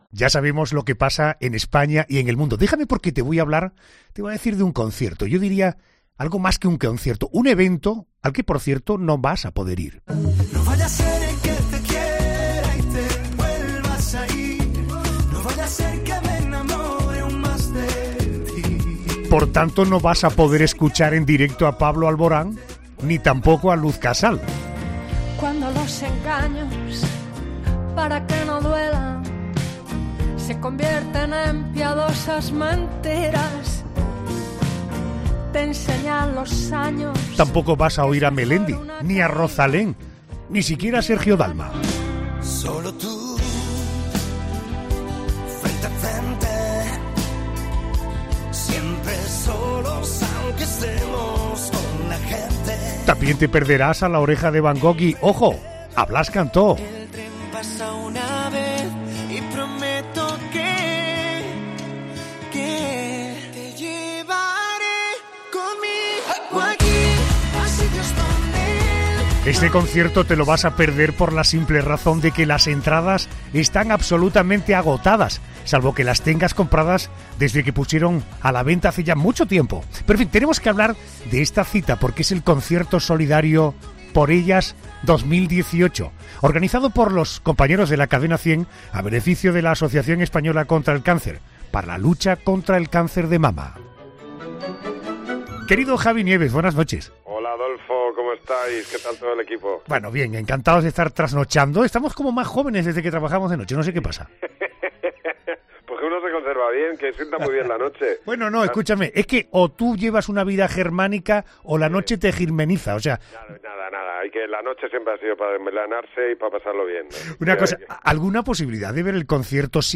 Javi Nieves se ha acercado a ‘La Noche de COPE’ y nos ha contado quiénes se van a sumar a la fiesta musical solidaria y entre ellos destacan entre otros Pablo Alboran , Melendi , Rozalén , Luz Casal , James Arthur , Sergio Dalma , La Oreja de Van Gogh , Matt Simons , Sofía Reyes , Clean Bandit y Blas Cantó entre otros.